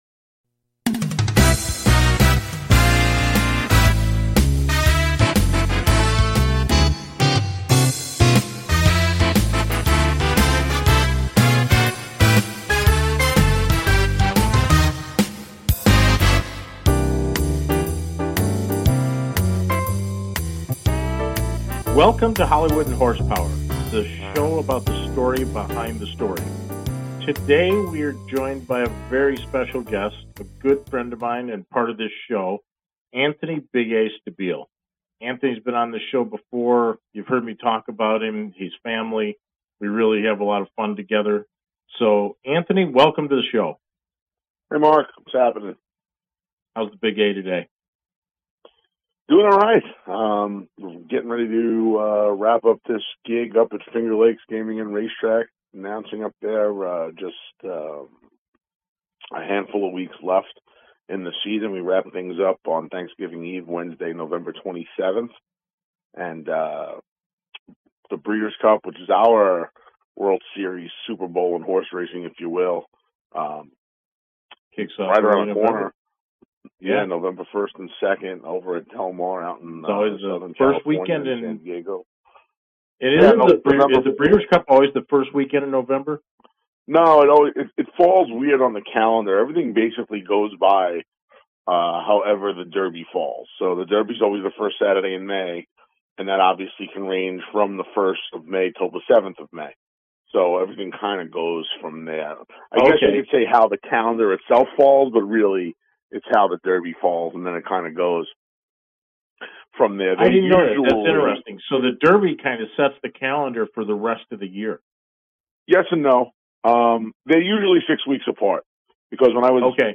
Talk Show Episode
It is where SNL meets The Tonight Show; a perfect mix of talk and comedy.